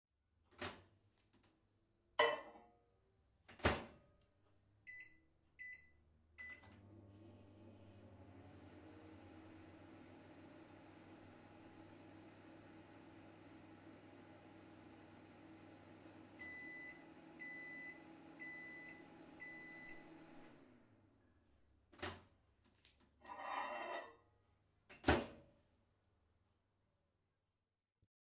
微波炉
描述：将杯子放在微波炉内，进行编程并打开。最后，微波炉发出哔哔声